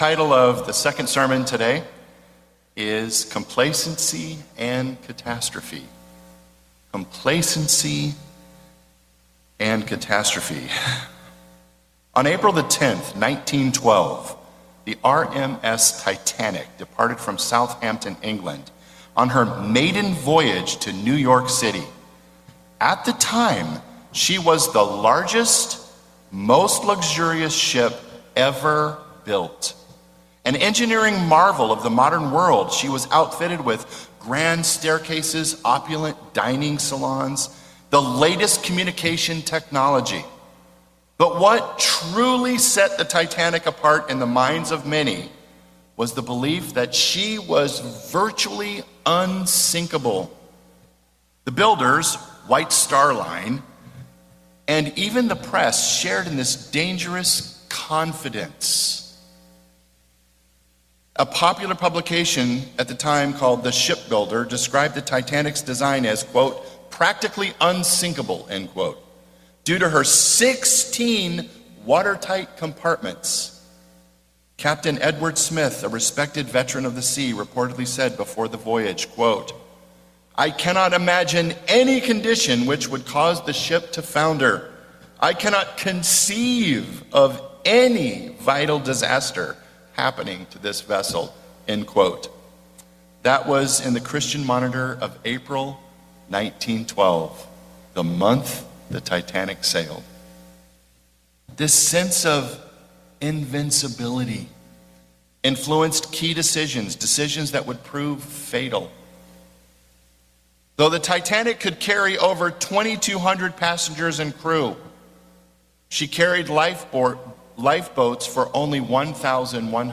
Complacency leaves you completely unprepared for crises, whether they be physical, moral, or eternal. The solution to complacency, as discussed in this sermon, involves staying attentive to the wisdom of God and opening yourself up to advice.